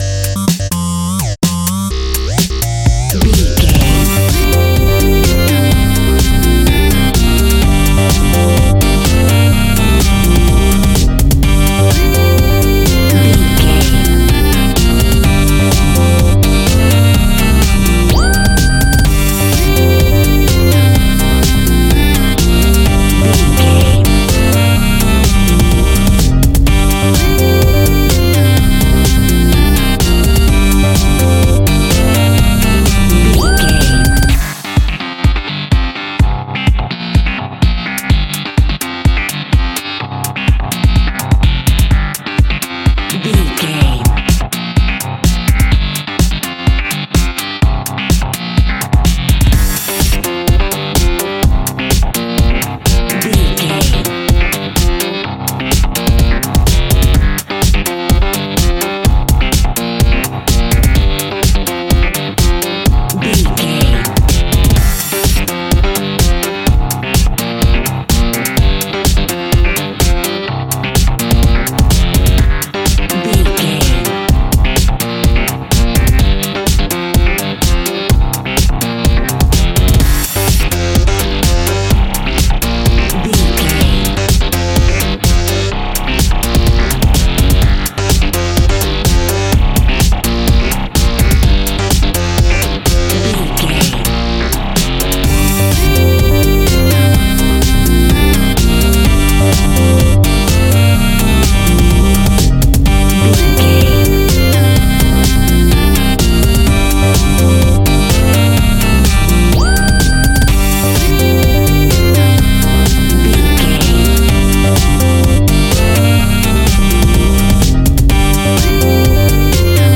Aeolian/Minor
funky
groovy
uplifting
driving
energetic
drums
bass guitar
synthesiser
electric piano
electro house
funky house
funky house instrumentals
synth bass
synth leads
percussion